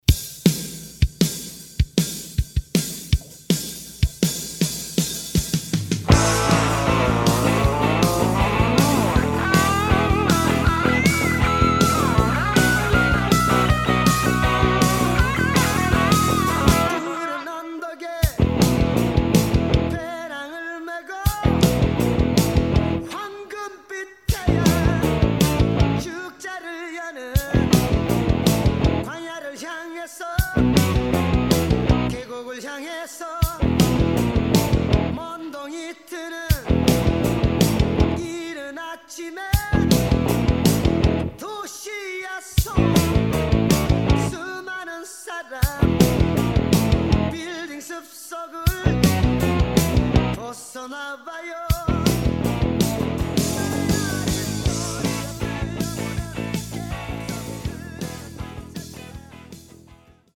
음정 원키
장르 가요 구분